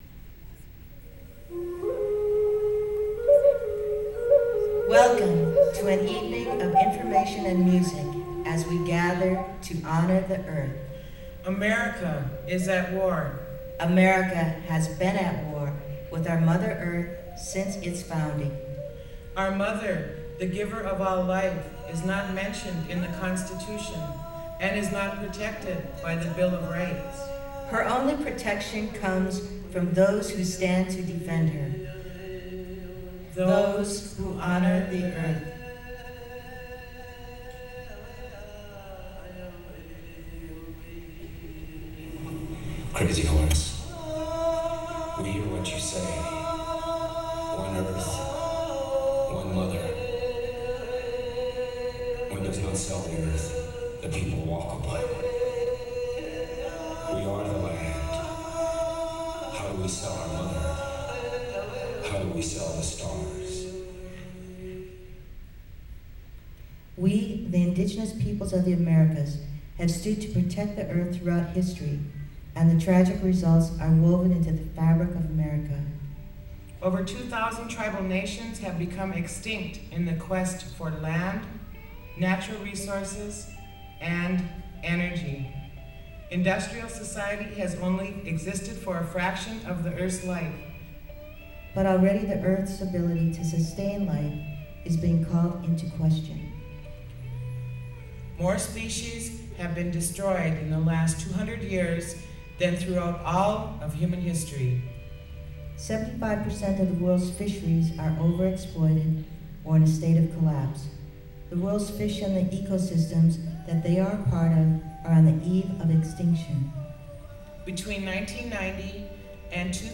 lifeblood: bootlegs: 2003-04-15: hub ballroom (university of washington) - seattle, washington (honor the earth benefit with winona laduke)